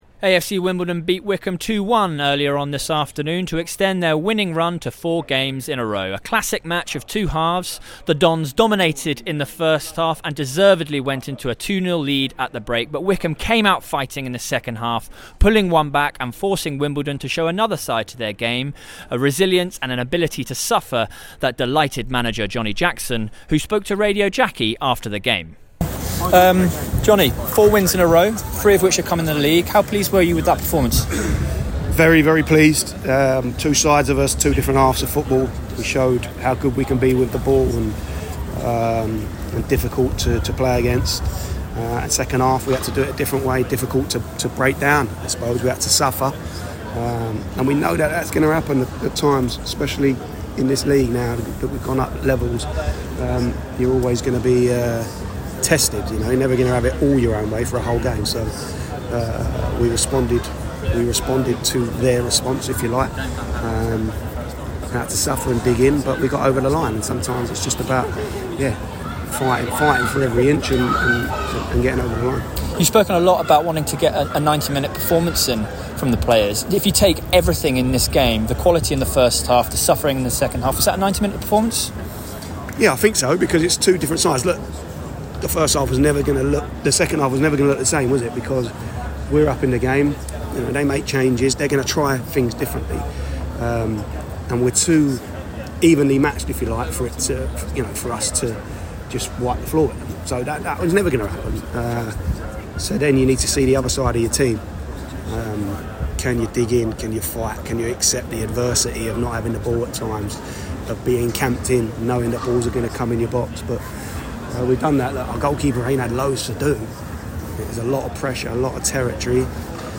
AFC Wimbledon v Wycombe Match Report.